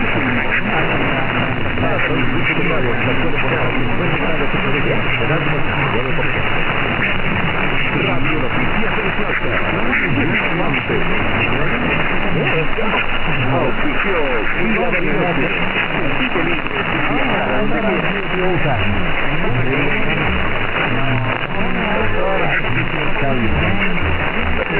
>Heard this morning in Alexandria, VA at 0904utc on 1030kHz:
>First heard this past March during the last big auroral event.
>Wellbrook 4-element phased delta loop array